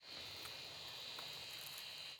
Camera_ZoomOut.wav